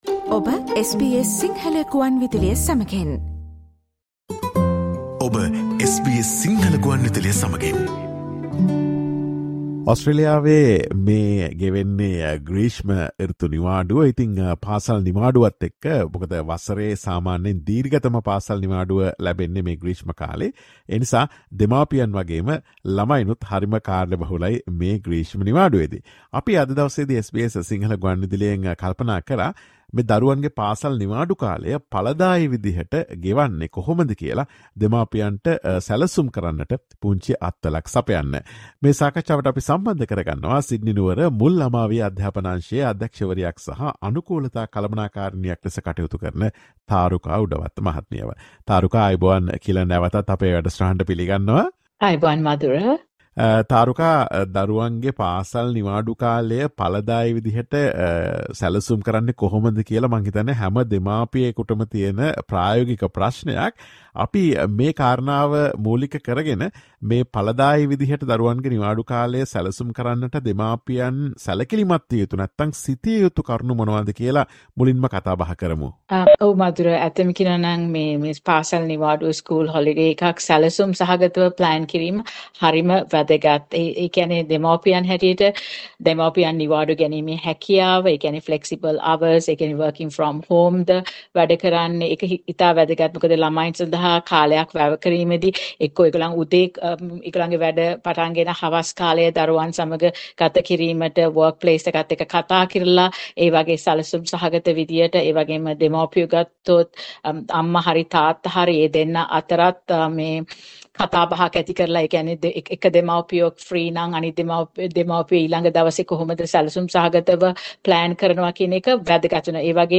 Listen to SBS Sinhala Radio's discussion on how parents can effectively plan children's school holidays.